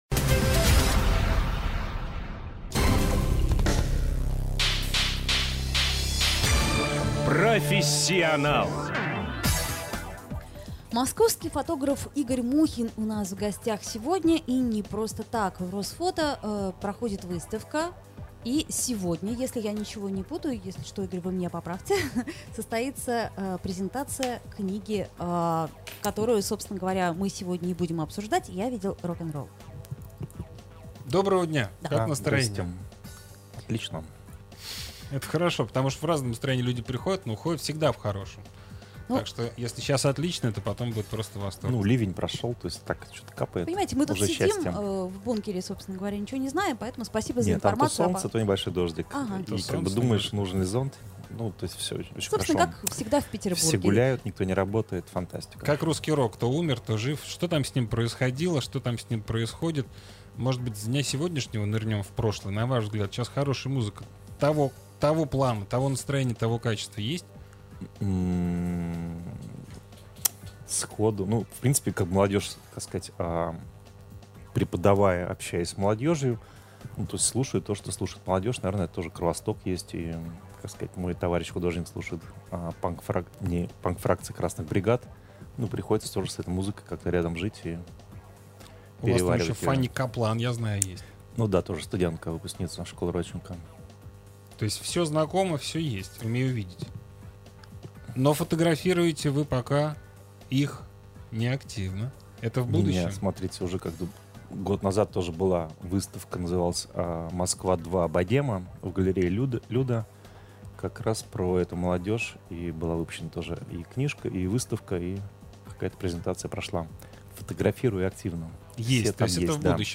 [Фонтанка.Офис] Полная запись эфира доступна на официальном канале "Фонтанки" на YouTube . Аудиоподкаст эфира: Цой гуляет по Москве, Курехин толкается, а Гребенщиков обнимается с Мамоновым.